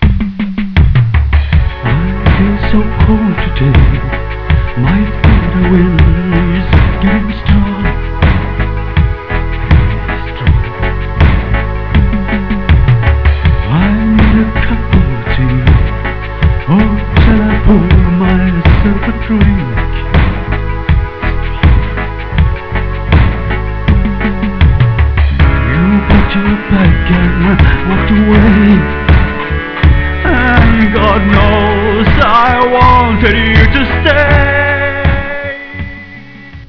Jeg beklager lydkvaliteten, men det er altså audio -"levende lyd" - som er omgjort til korte 8-bits wave-filer for at de ikke skal ta "for ever" å laste ned.
Spilt inn med hjelp av Yamaha PSS-796 og Tascam Porta 5.